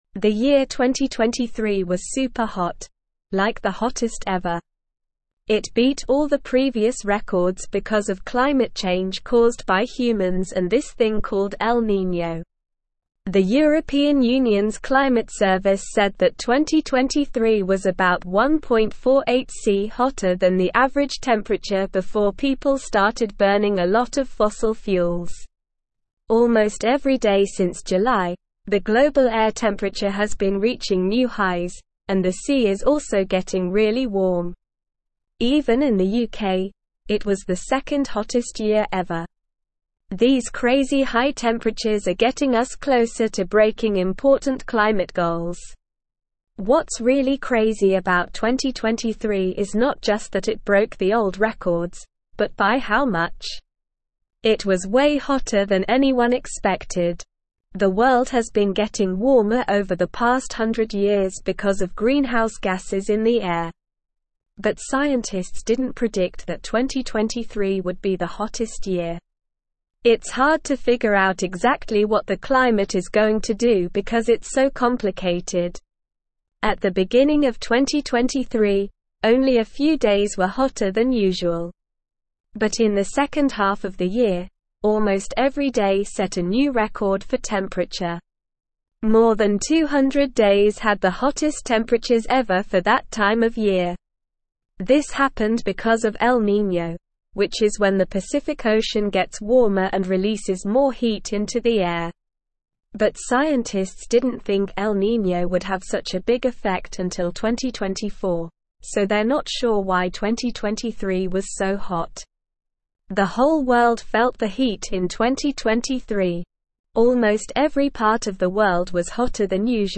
Slow
English-Newsroom-Upper-Intermediate-SLOW-Reading-2023-Hottest-Year-on-Record-Urgent-Climate-Action-Needed.mp3